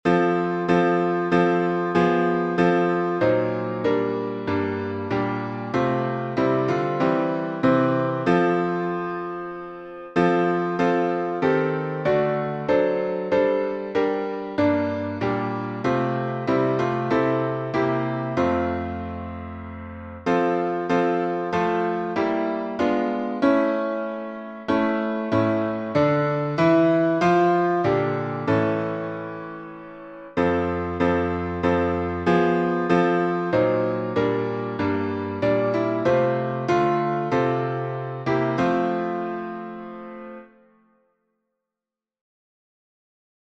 #1022: O Little Town of Bethlehem — alternate chords — St. Louis | Mobile Hymns